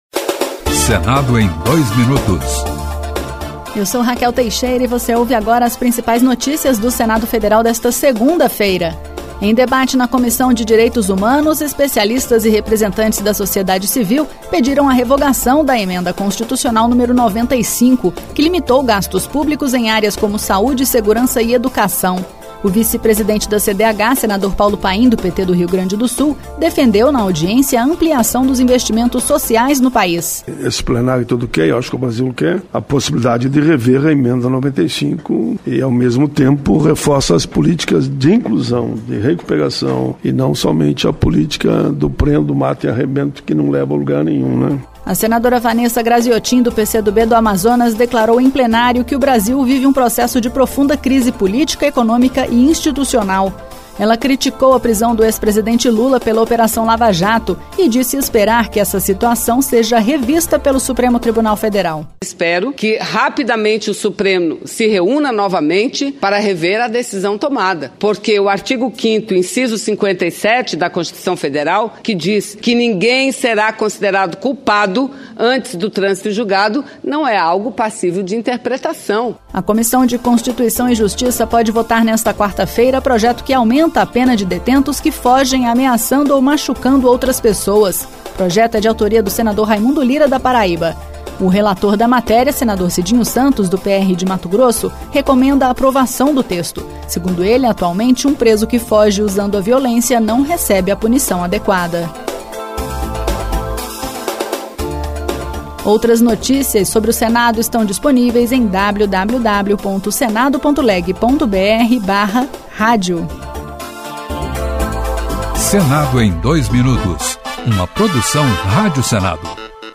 Boletim.leg